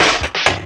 Hard_Shuffle.wav